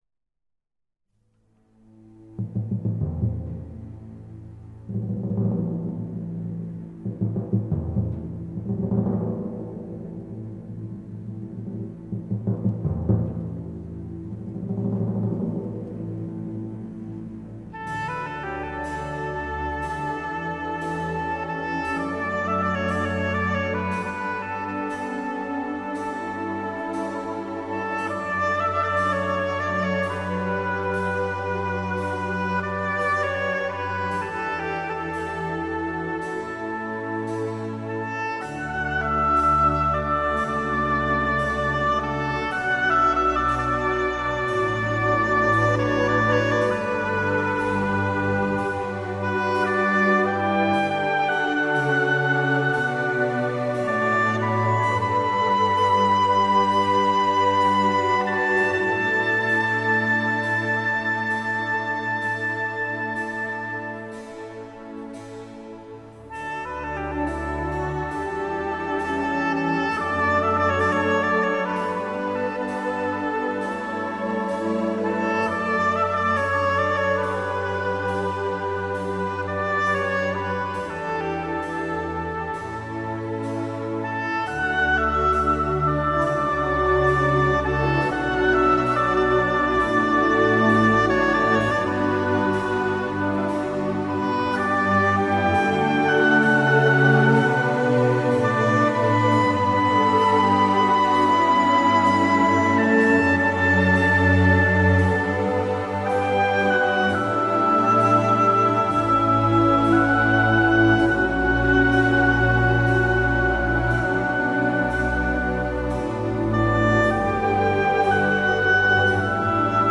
電影配樂